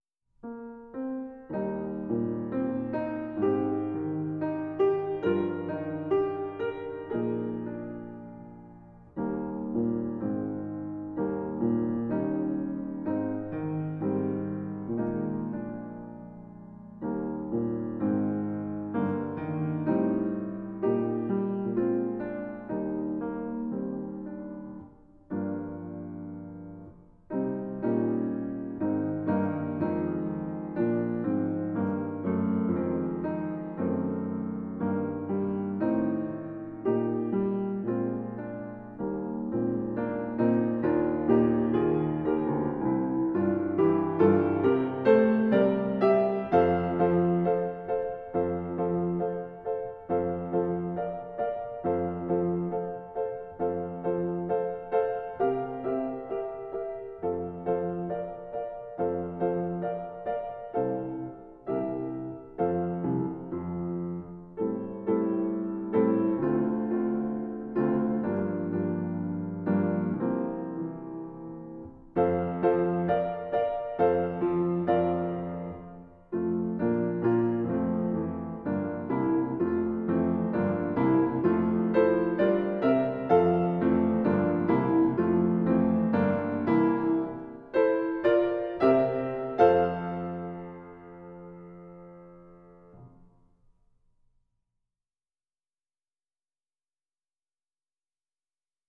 SOLO BRASS
B♭ Accompaniment Track